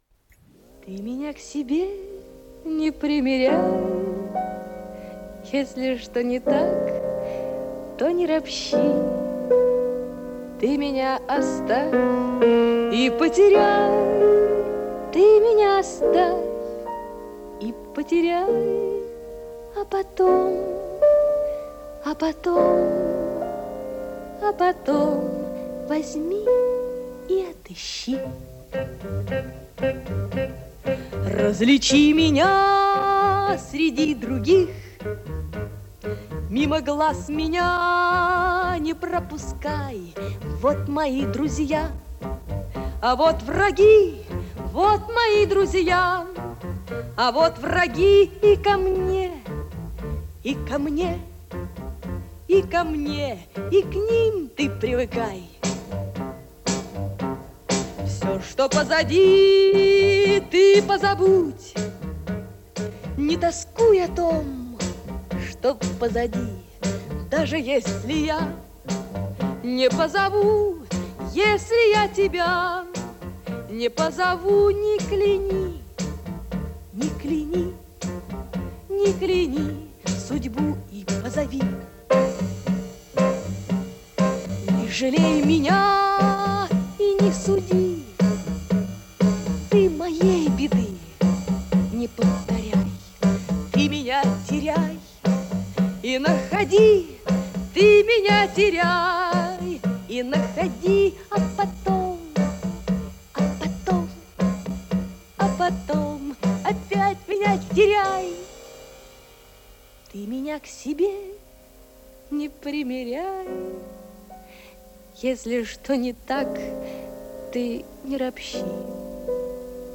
Но с тембром голоса 1968 года, а не нынешним)))Сеть молчит